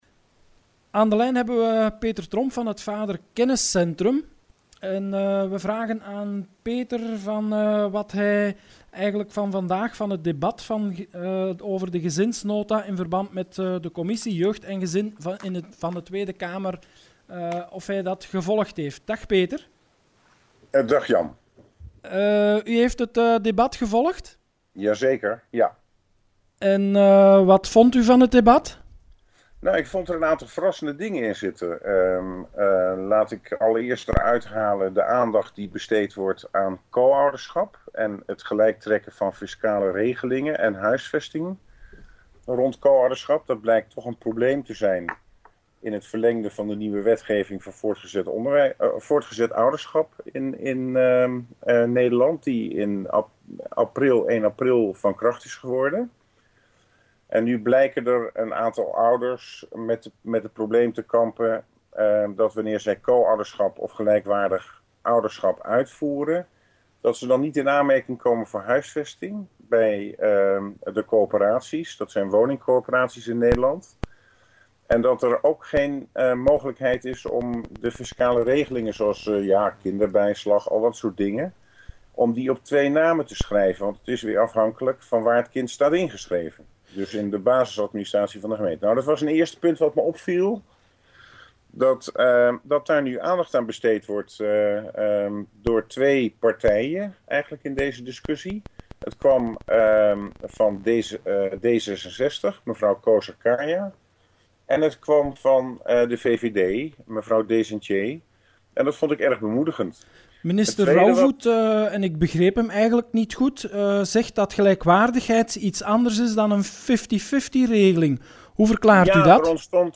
Behandeling Kabinetsnota Gezinsbeleid in relatie tot scheidingen in de Tweede Kamercommissie voor Jeugd en Gezin op 5 oktober 2009
Bron: Tweede Kamer, Commissie Jeugd en Gezin, Behandeling Kabinetsnota Gezinsbeleid, 5 oktober 2009